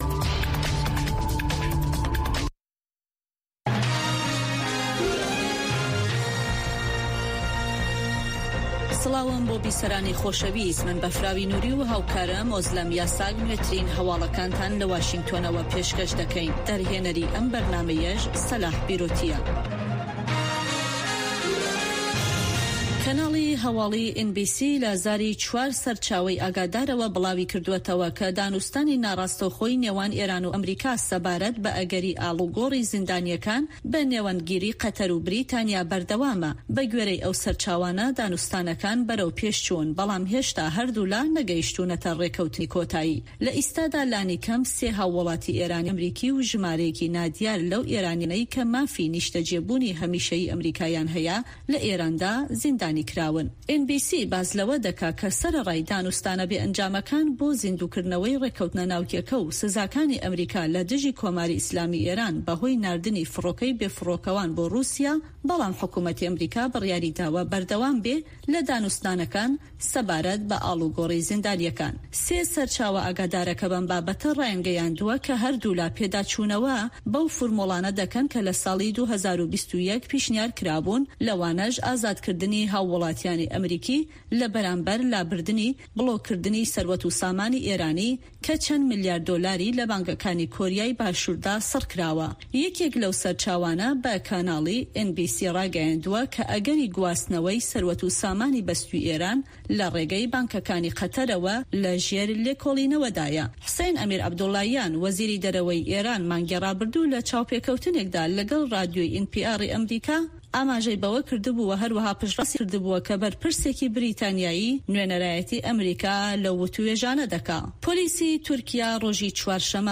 هەواڵە جیهانییەکان 1